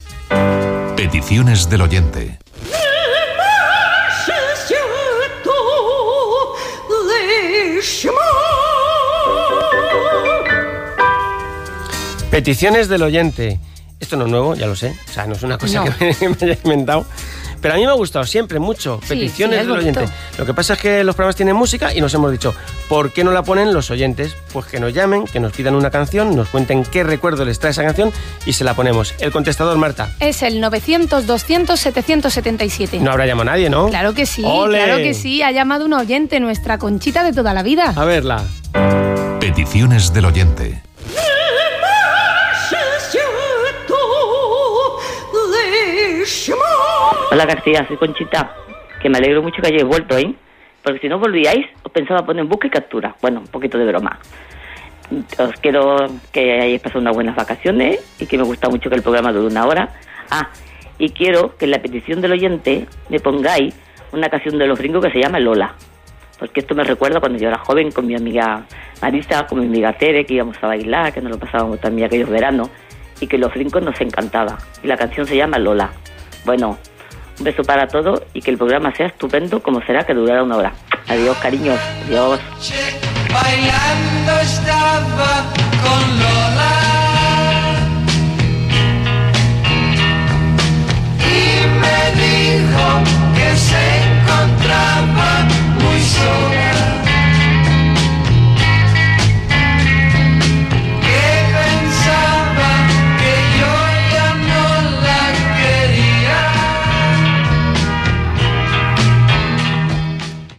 "Peticiones del oyente", telèfon del contestador, trucada d'una oïdora demanant una cançó
Entreteniment